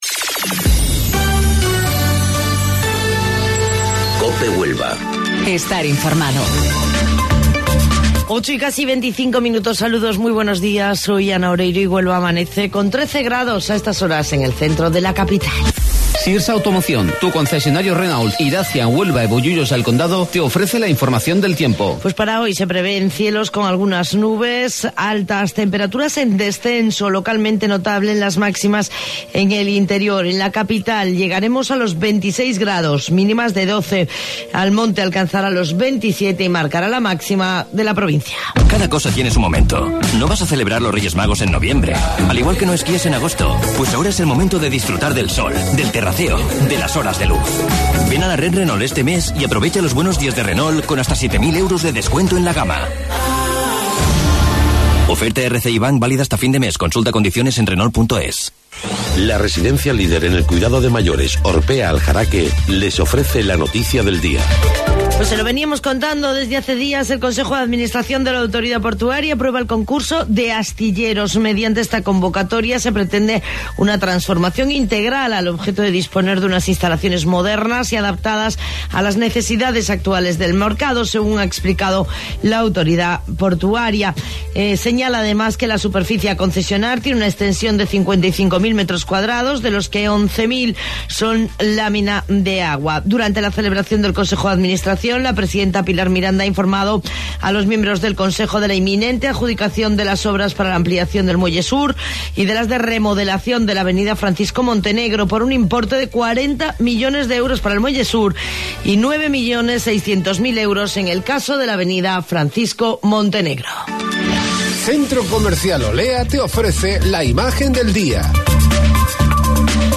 AUDIO: Informativo Local 08:25 del 17 de Mayo